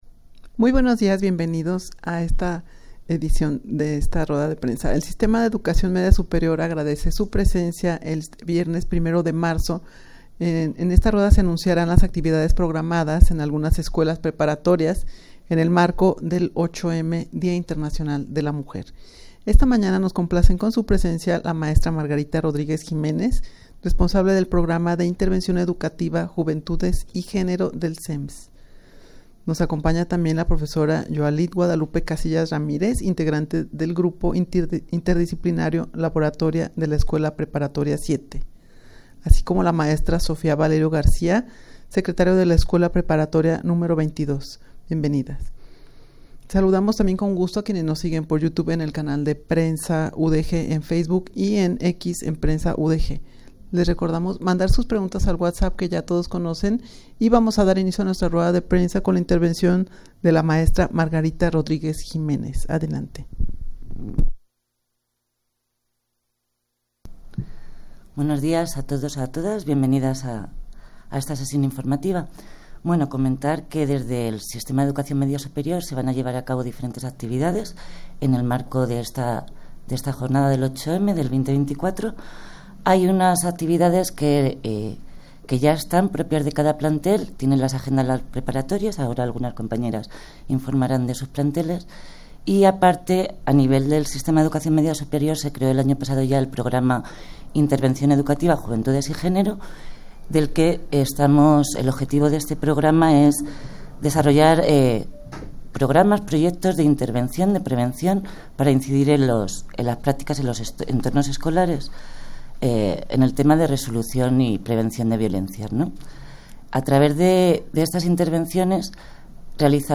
Audio de la Rueda de Prensa